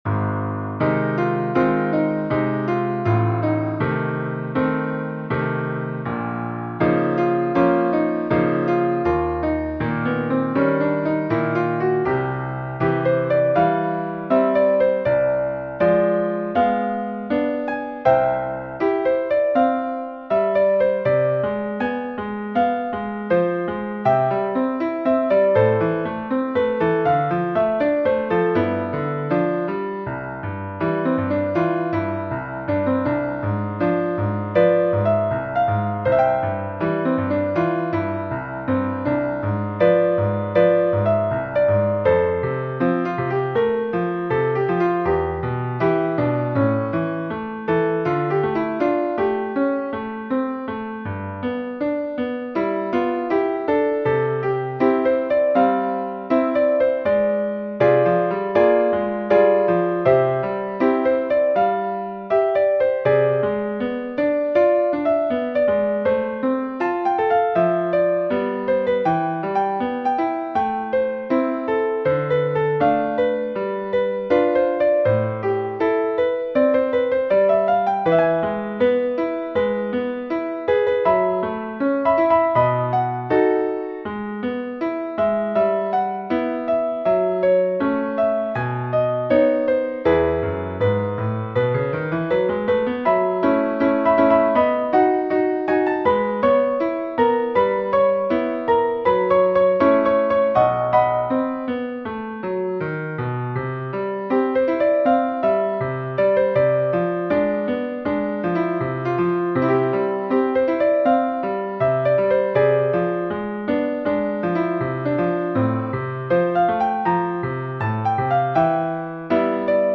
Partitura para piano / Piano score (pdf)